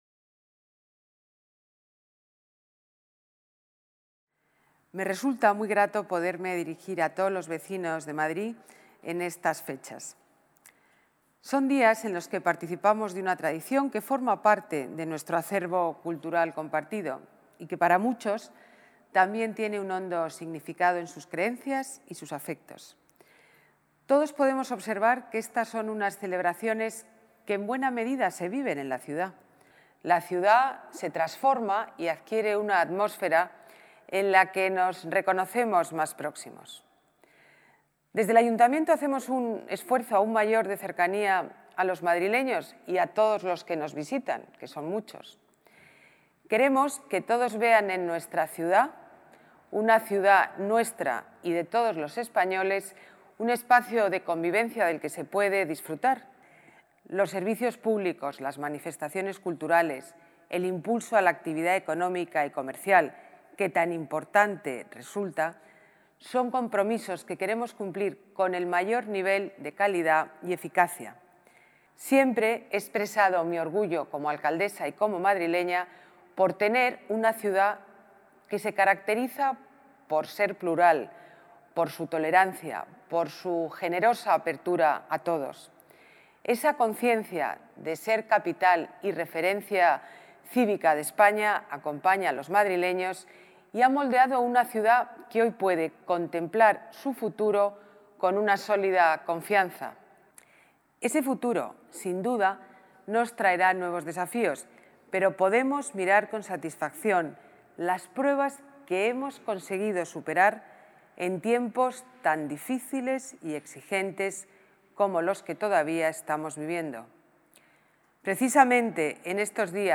Mensaje de Navidad de la alcaldesa de Madrid, Ana Botella - Ayuntamiento de Madrid